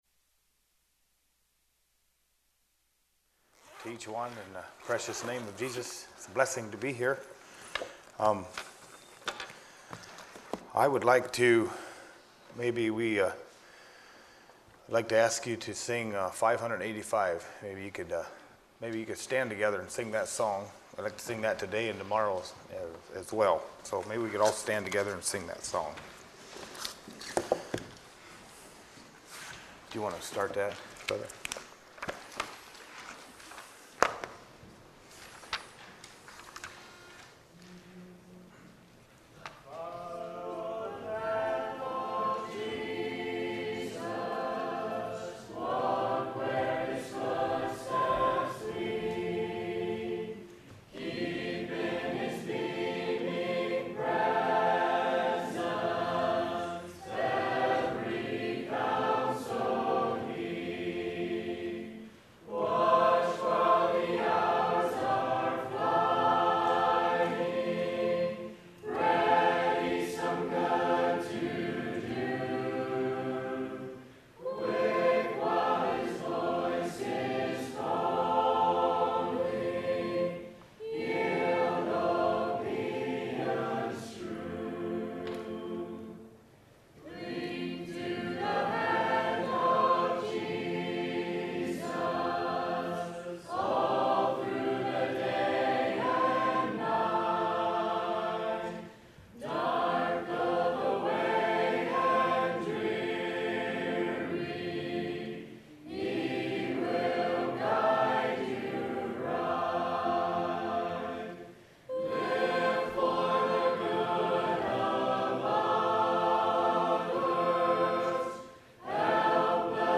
Youth Meeting Messages